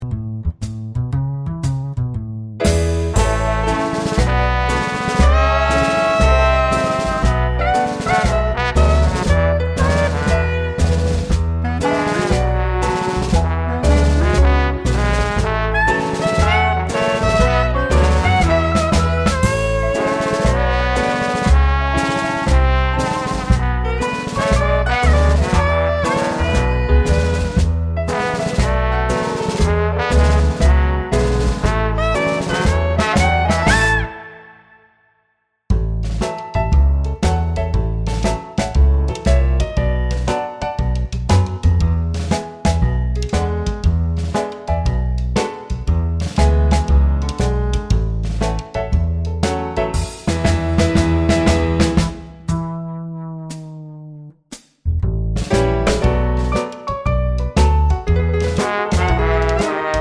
no Backing Vocals Jazz / Swing 4:03 Buy £1.50